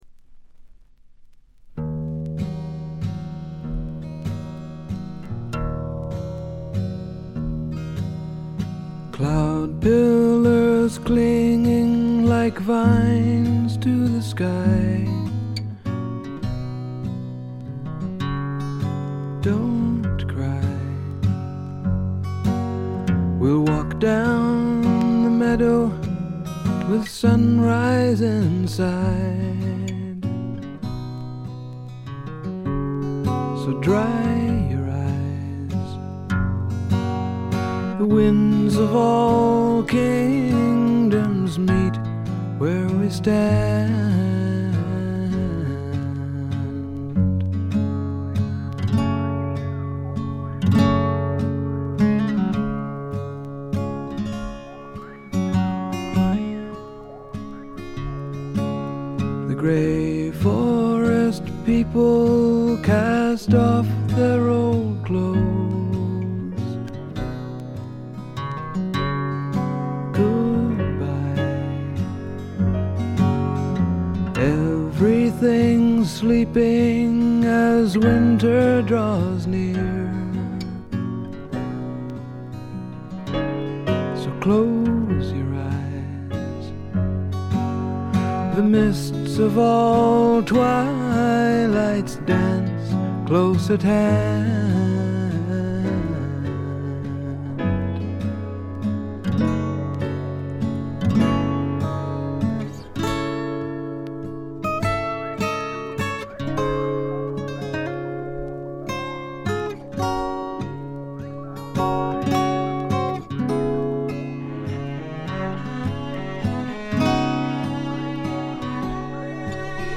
散発的なプツ音少し。
試聴曲は現品からの取り込み音源です。